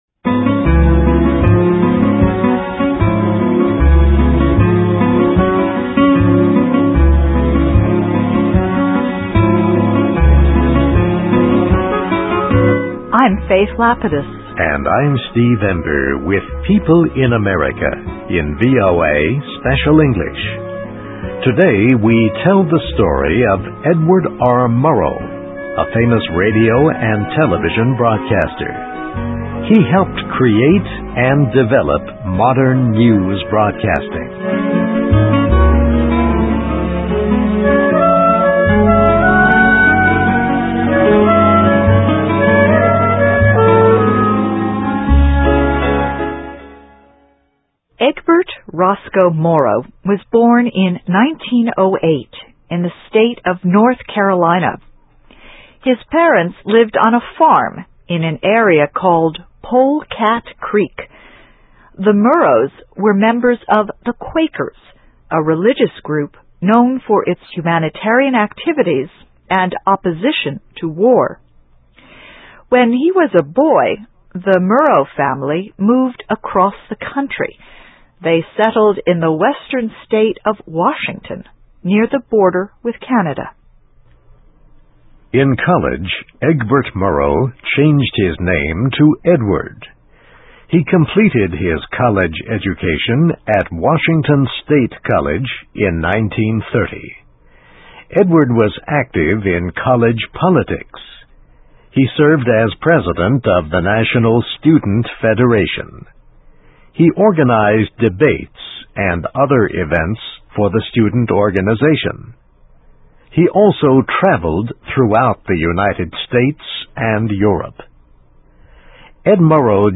Biography - Edward R. Murrow, 1908-1965: The Famous Radio and Television Reporter Helped Create Modern News Broadcasting (VOA Special English 2008-05-24)
Listen and Read Along - Text with Audio - For ESL Students - For Learning English